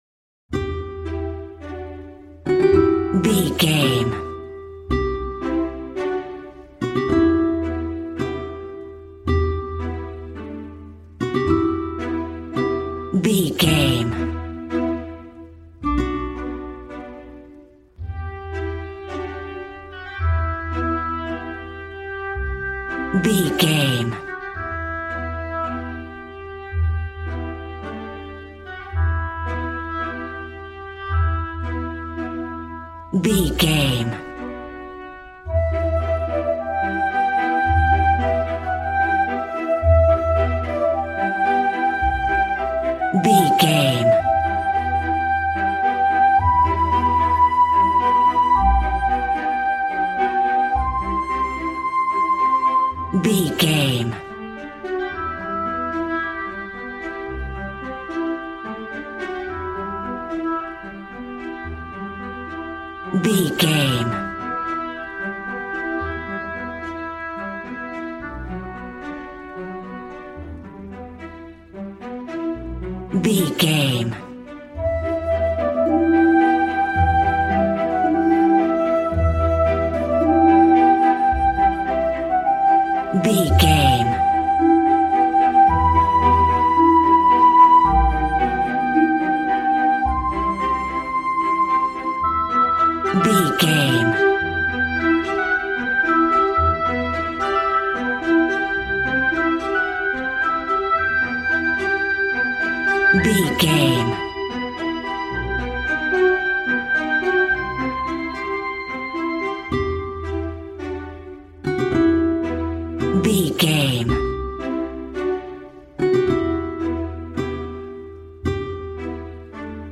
A warm and stunning piece of playful classical music.
Regal and romantic, a classy piece of classical music.
Aeolian/Minor
G♭
regal
piano
violin
strings